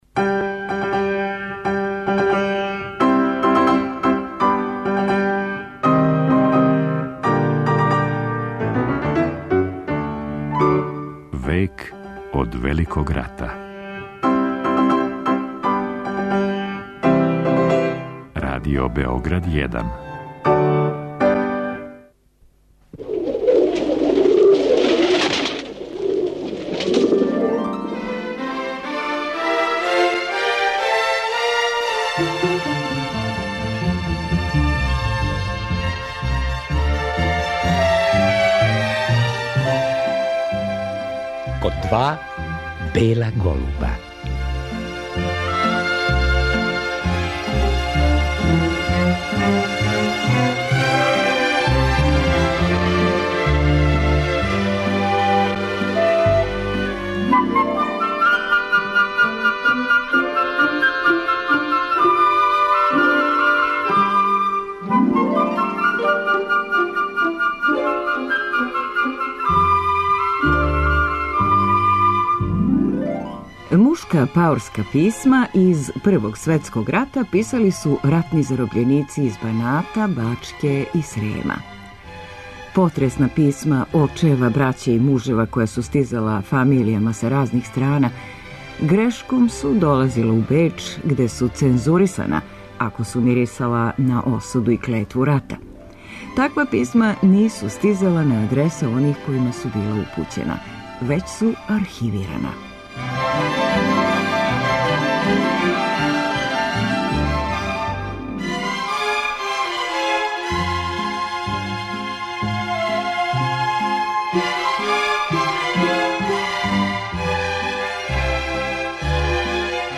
Нека од њих прочитаћемо у овој емисији.